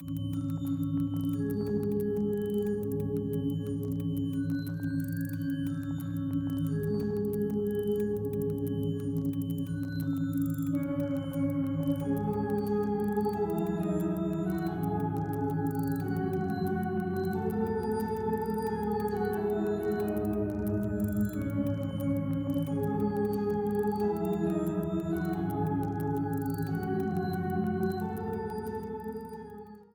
Ripped from the game